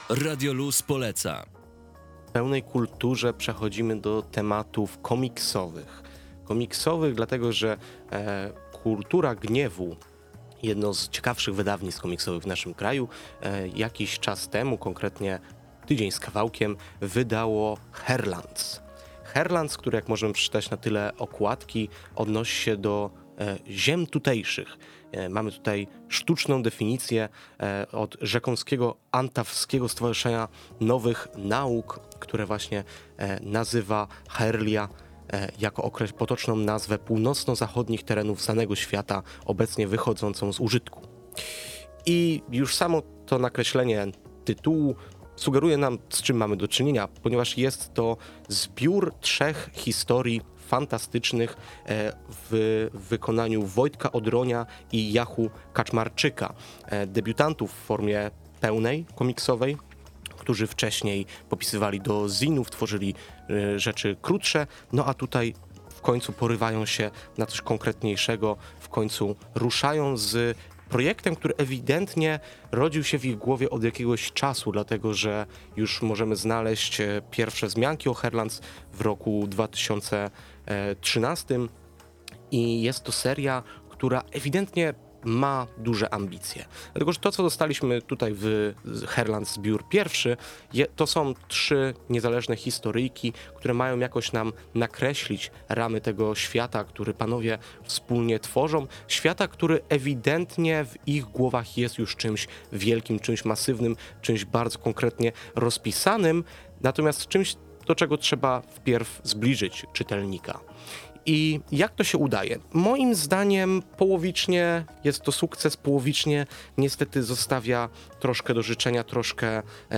Recenzja Haerelands